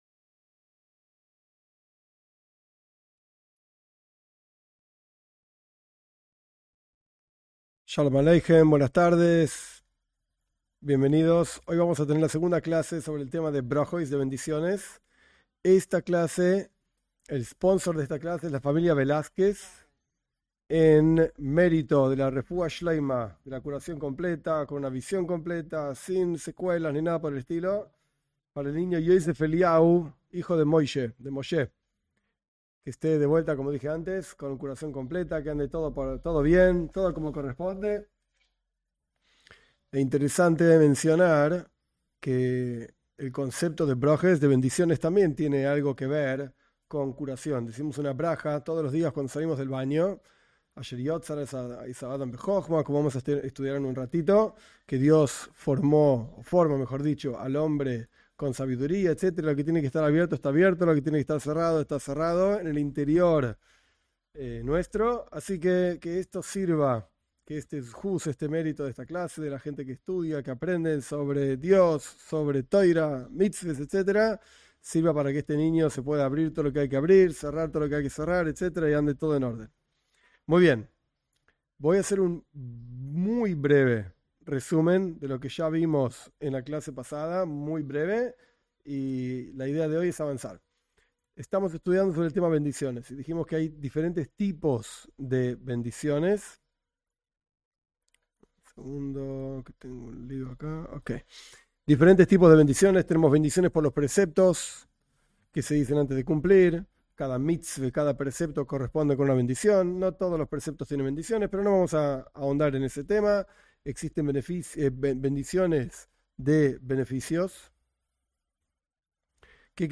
En estas clases se analiza con detenimiento las bendiciones en el judaísmo y cómo se aplican a no judíos.